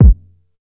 Go To Kick 3k.wav